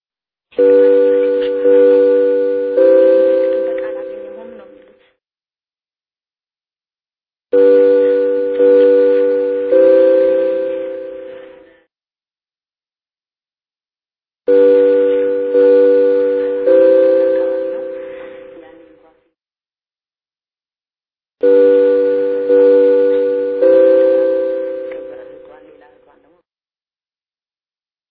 musical notes B. B. C.
bbc tones.mp3